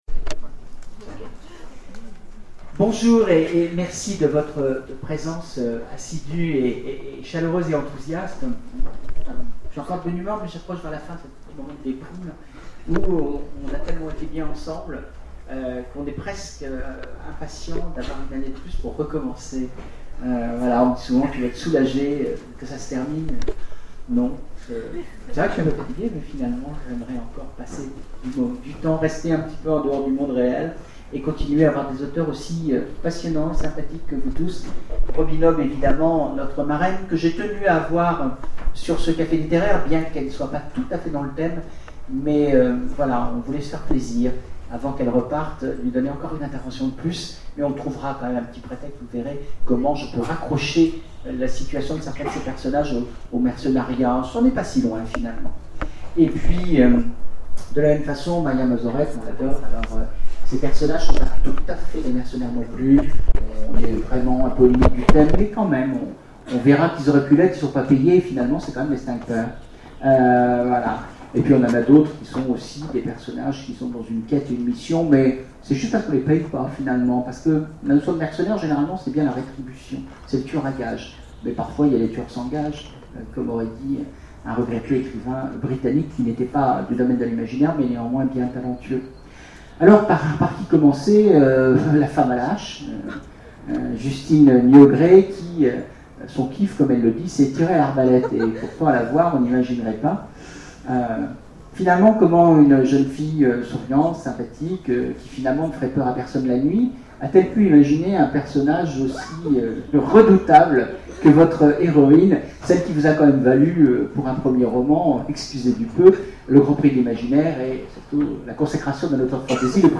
Imaginales 2011 : Conférence Profession mercenaire !
Voici l'enregistrement de la conférence "Profession mercenaire !"